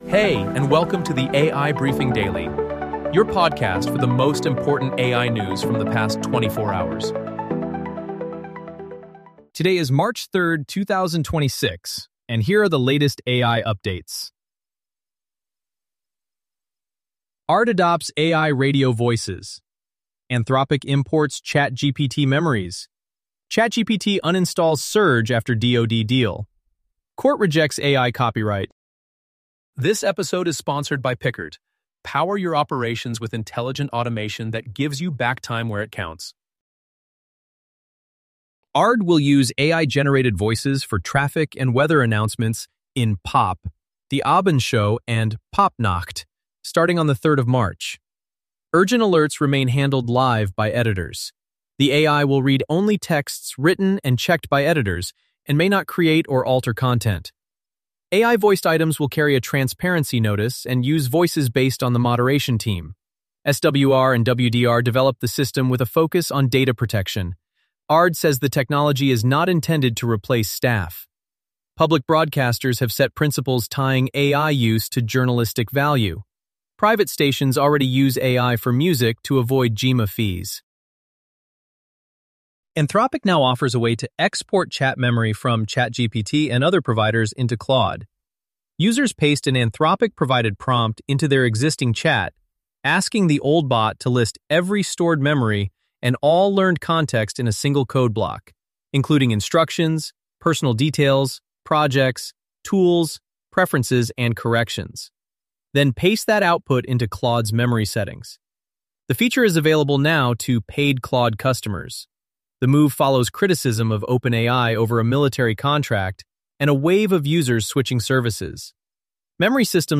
Unique: Our podcast is 100% AI-generated - from research to production to upload.